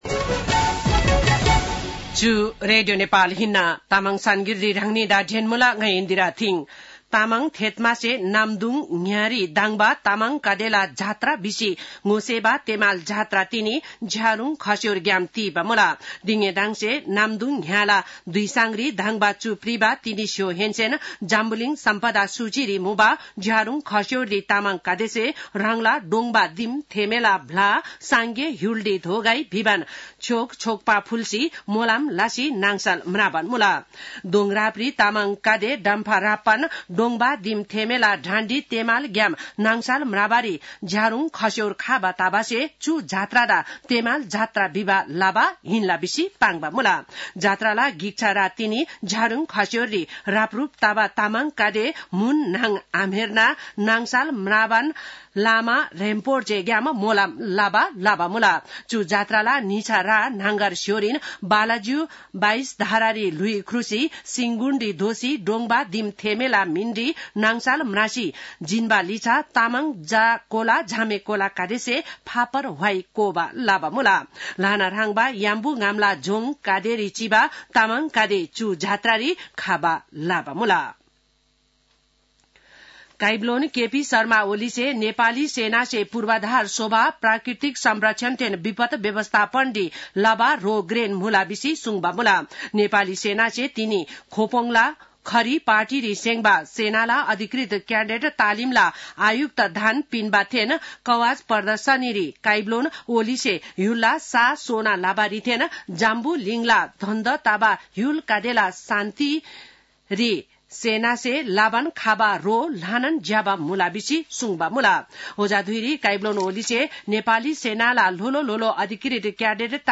An online outlet of Nepal's national radio broadcaster
तामाङ भाषाको समाचार : २९ चैत , २०८१
Tamang-news-12-29.mp3